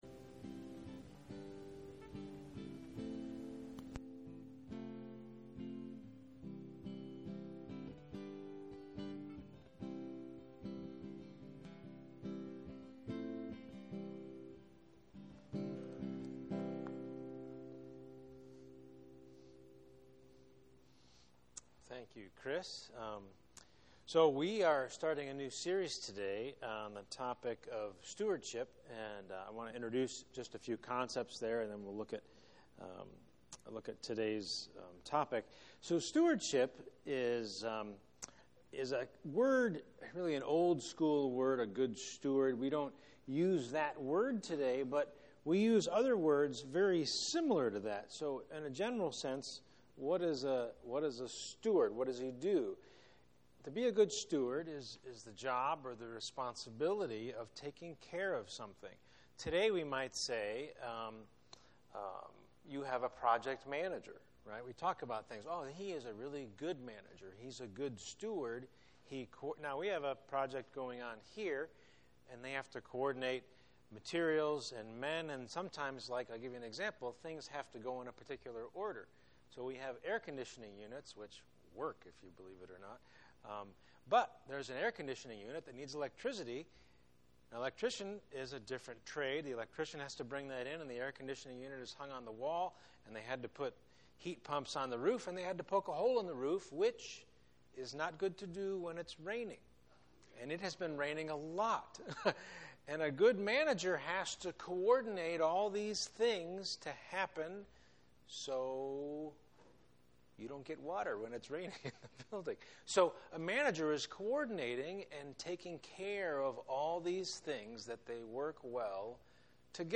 Faithfulness & Stewardship Service Type: Sunday Morning %todo_render% « Ephesians 1:19-21 Praying for believers to know His power Giving